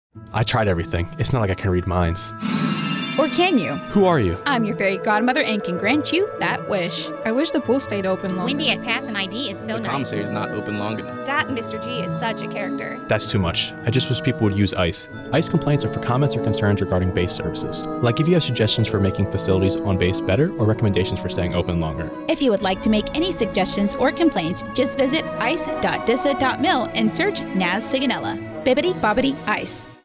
NAVAL AIR STATION SIGONELLA, Italy (March 22, 2024) A radio spot describing how to submit complaints to Naval Air Station Sigonella using the Interactive Customer Evaluation website.